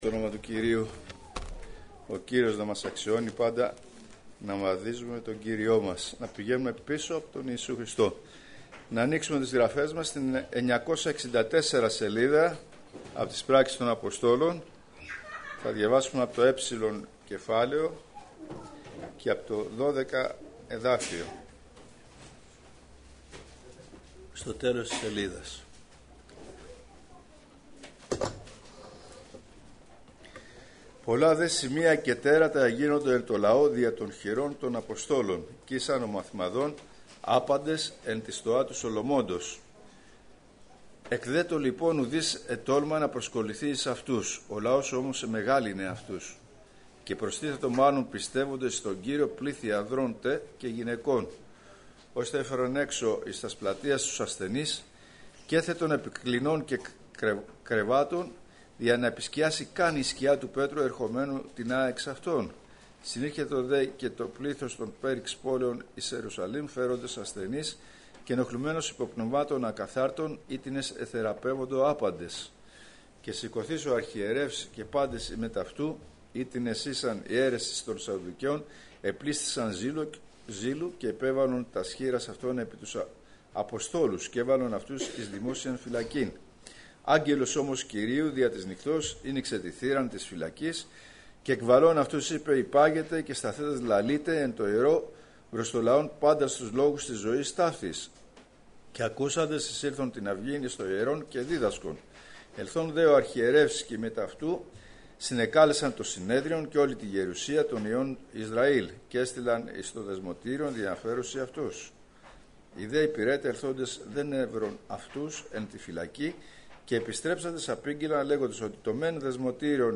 Κηρύγματα Ημερομηνία